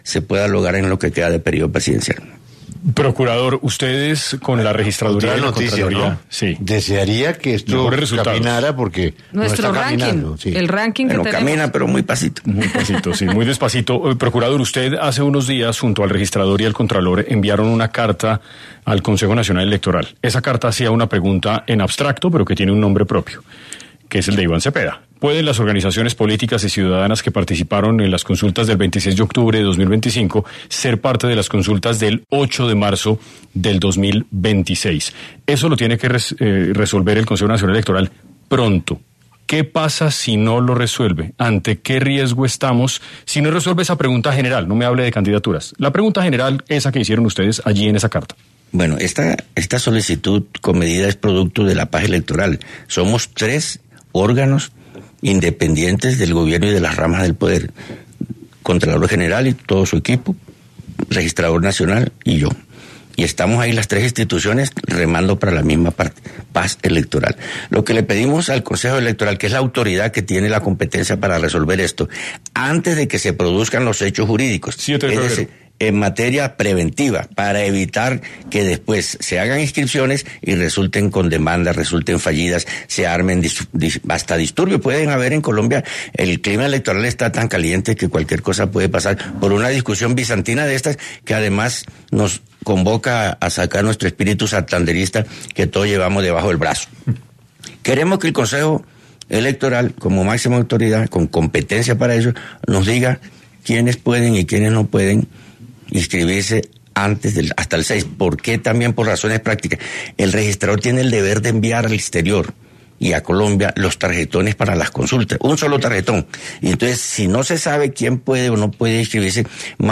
Gregorio Eljach habló en 6AM W sobre la carta enviada al Consejo Nacional Electoral sobre las consultas.